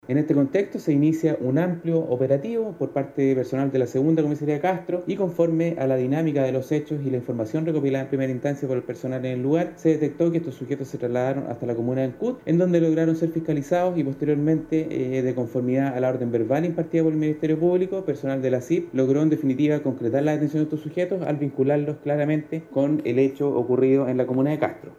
Tras la huida de los ladrones, se activó un operativo de carácter provincial, el que rindió frutos cuando ambos fueron ubicados en Ancud, donde se procedió a su detención al comprobarse que eran quienes habían asaltado la estación de servicio, indicó el oficial.